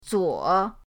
zuo3.mp3